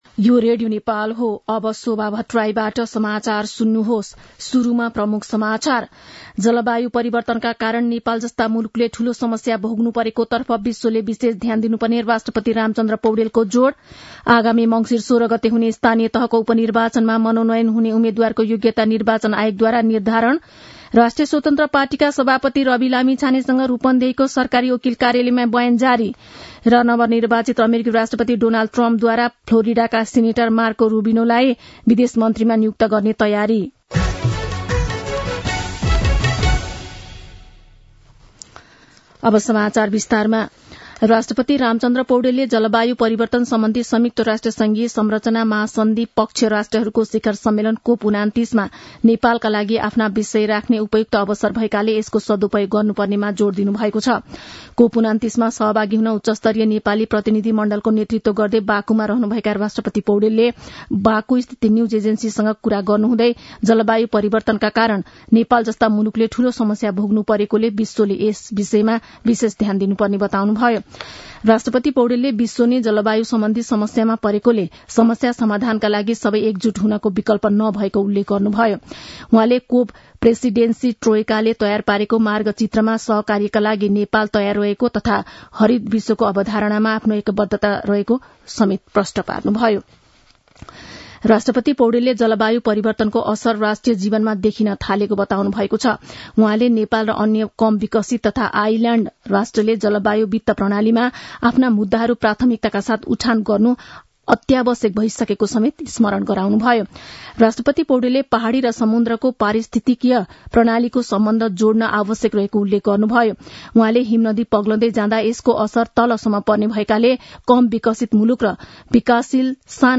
दिउँसो ३ बजेको नेपाली समाचार : २८ कार्तिक , २०८१
3-pm-news-1-1.mp3